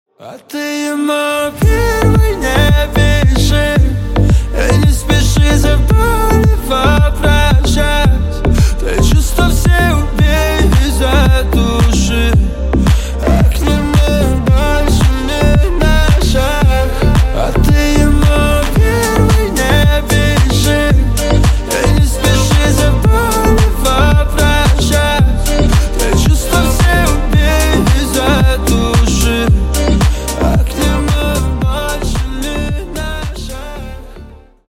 # Поп Рингтоны Новинки